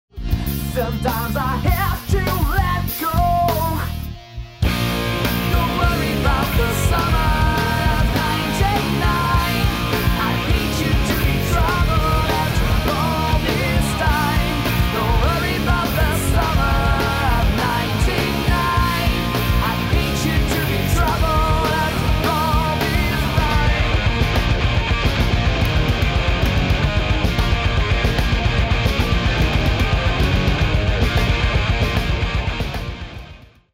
Good songs formed in power chord guitar arrangements.....